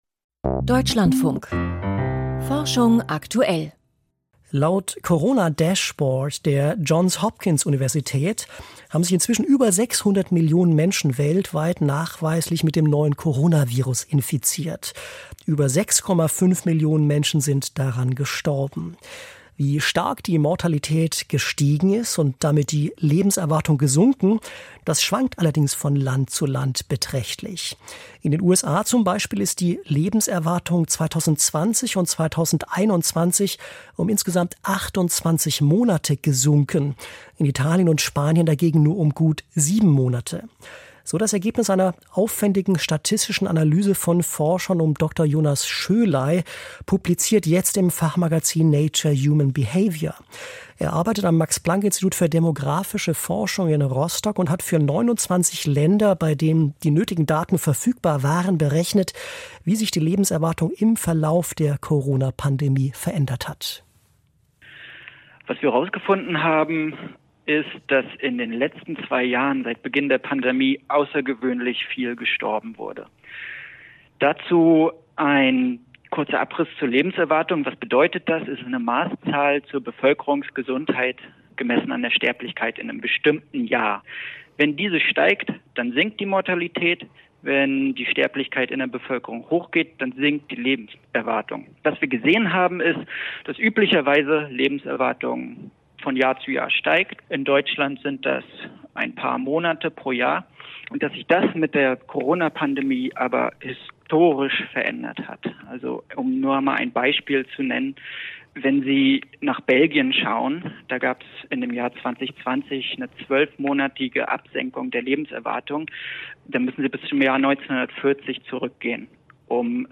Deutschlandfunk. Radio interview.